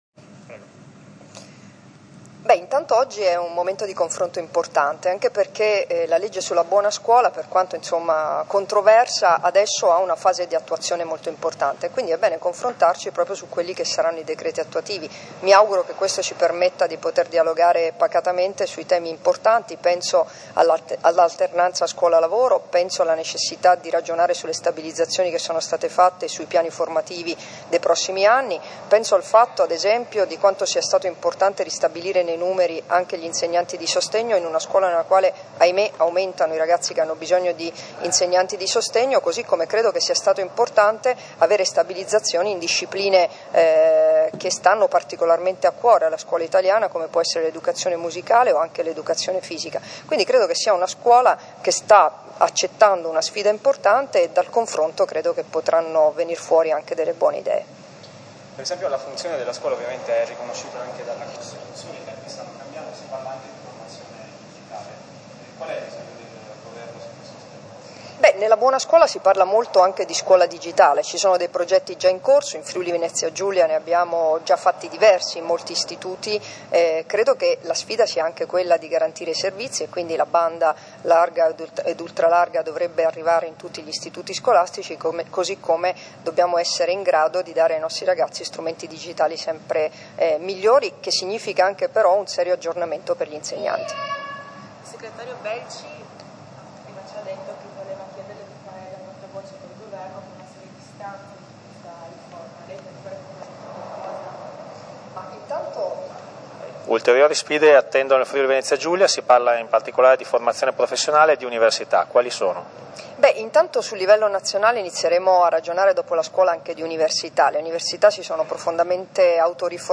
Intervento della presidente alla tavola rotonda "Cittadinanza e Costituzione: il ruolo della Scuola pubblica statale", organizzata dalla FLC CGIL a Gorizia.
Dichiarazioni di Debora Serracchiani (Formato MP3) [780KB]